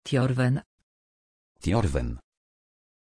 Aussprache von Tjorven
pronunciation-tjorven-pl.mp3